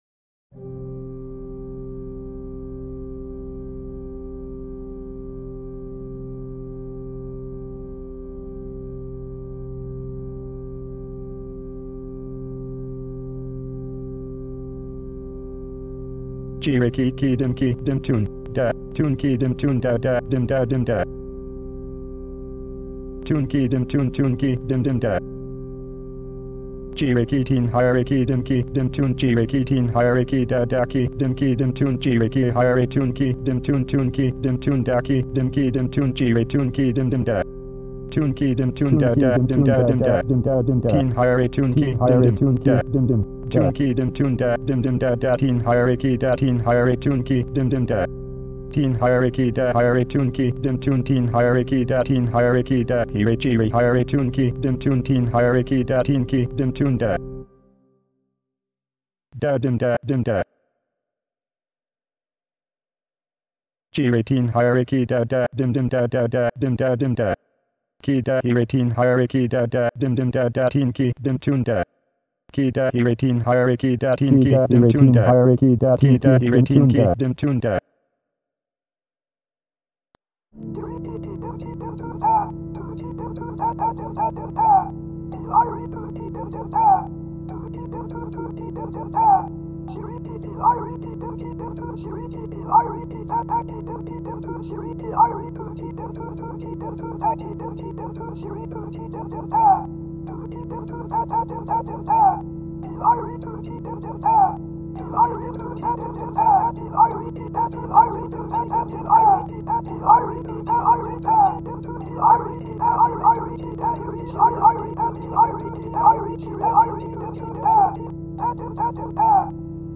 text/sound compositions with synthesized speech.